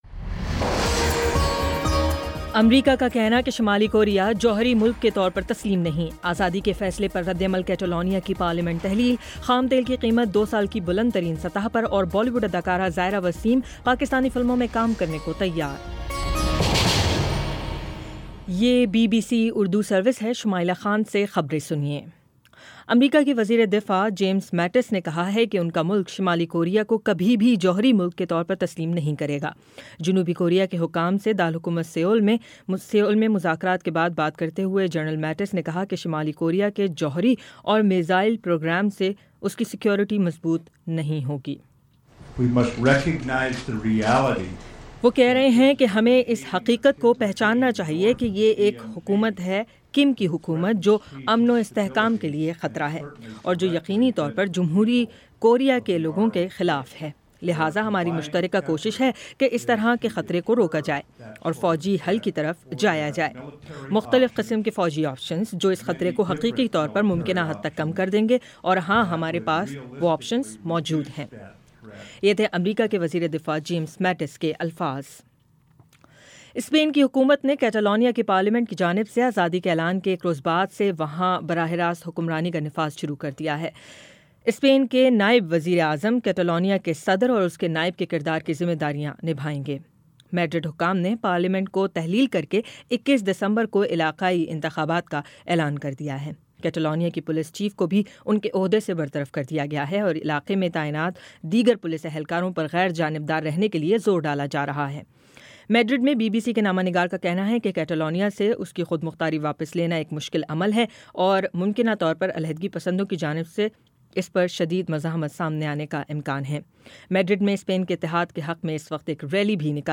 اکتوبر 28 : شام پانچ بجے کا نیوز بُلیٹن